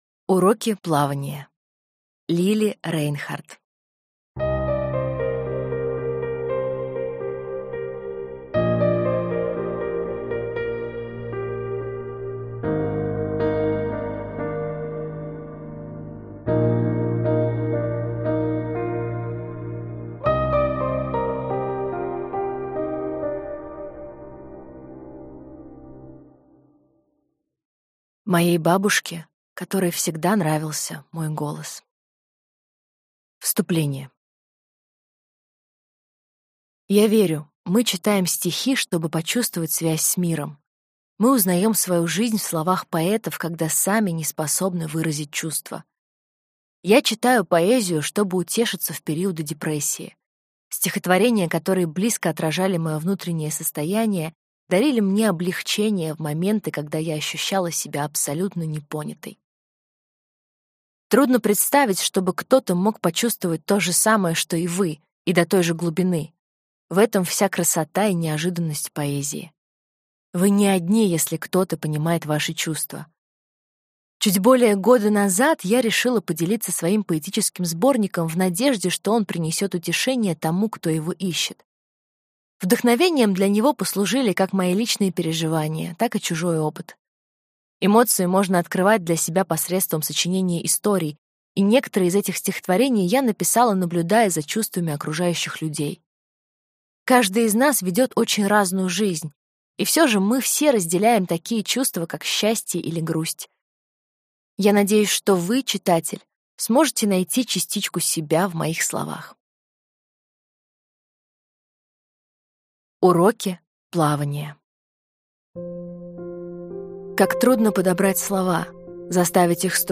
Аудиокнига Уроки плавания | Библиотека аудиокниг
Прослушать и бесплатно скачать фрагмент аудиокниги